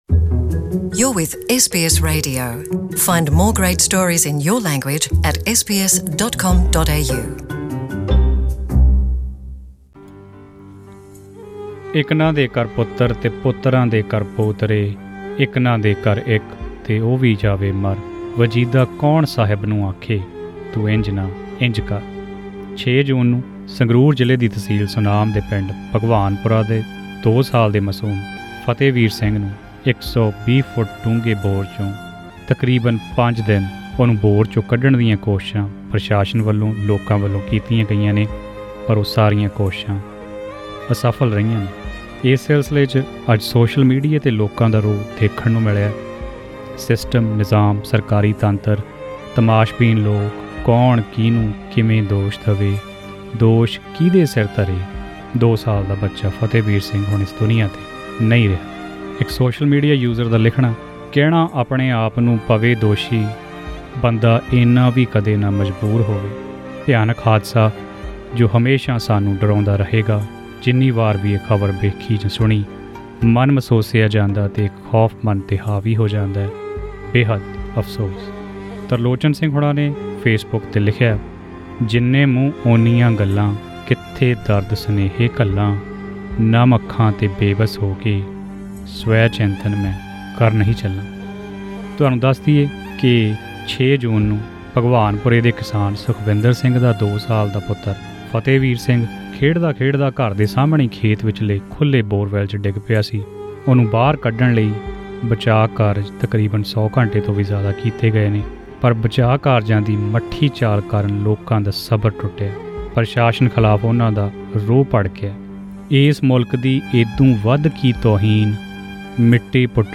For more information, listen to a report in Punjabi, by clicking the audio link above.